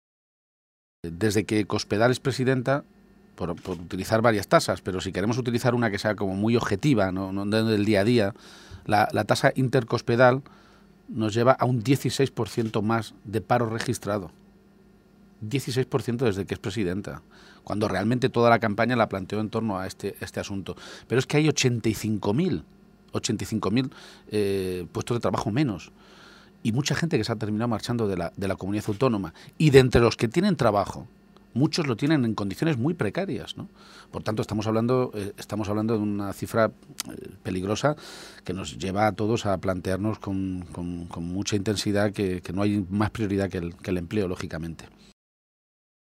Audio Page-entrevista Ser Tarancon-datos paro